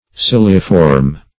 Search Result for " ciliiform" : The Collaborative International Dictionary of English v.0.48: Ciliform \Cil"i*form\, Ciliiform \Cil"i*i*form`\, a. [Cilium + -form] Having the form of cilia; very fine or slender.